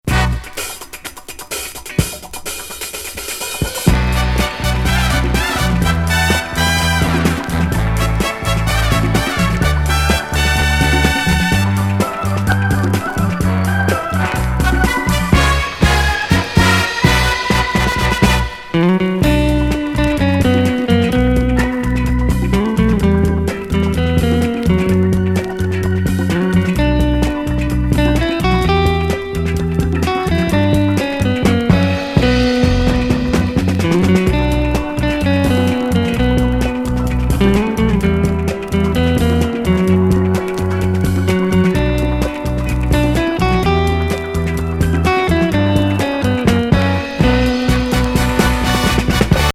リスニング・ギター・アレンジしたインスト作。